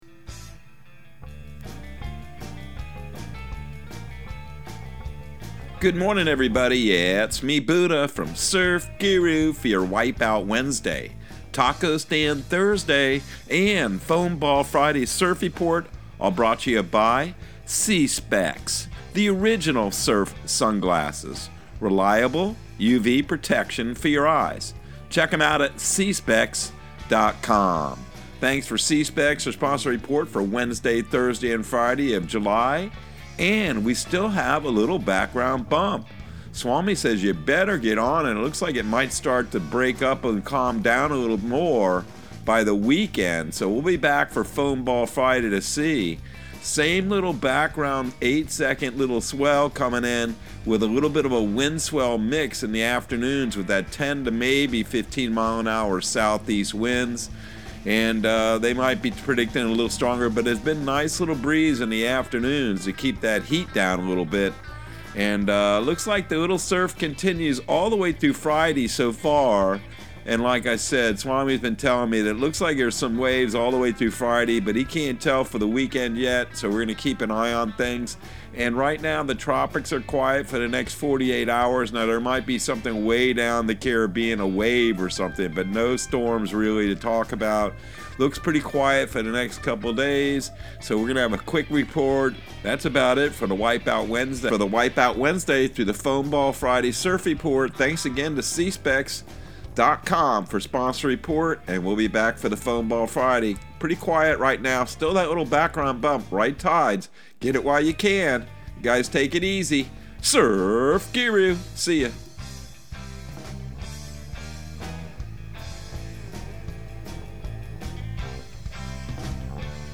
Surf Guru Surf Report and Forecast 07/06/2022 Audio surf report and surf forecast on July 06 for Central Florida and the Southeast.